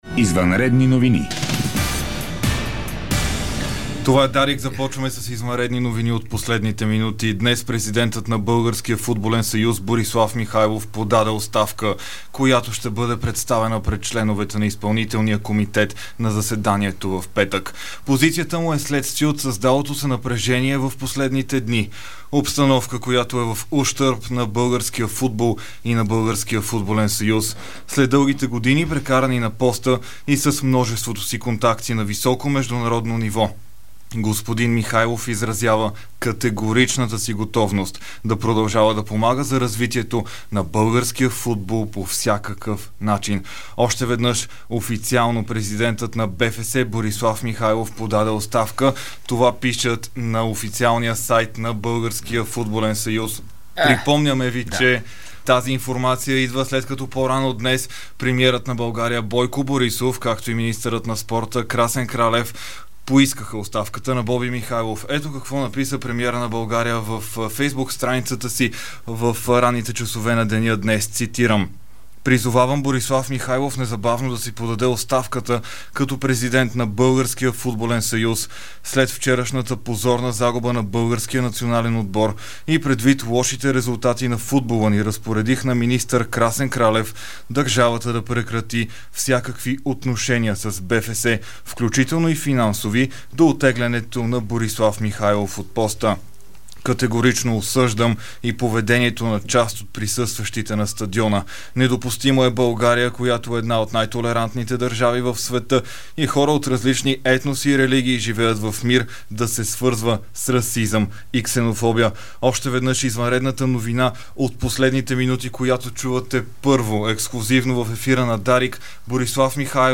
Извънредни новини